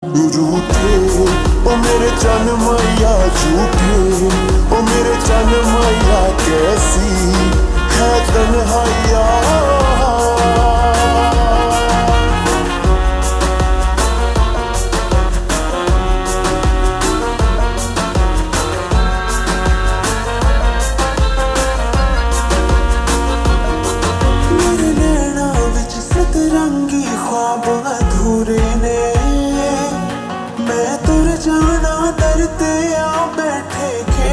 Slow Reverb Version
• Simple and Lofi sound
• Crisp and clear sound